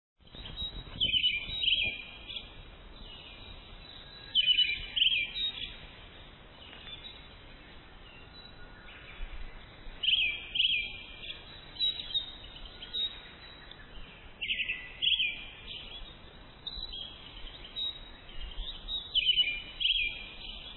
ツ　グ　ミ　属   ア カ ハ ラ １　1-05-16
鳴 き 声：キョッ、キョ。キョキョという声で鳴き、ツーという声も発する。キョロンキョロン、チリリと囀る。
鳴き声１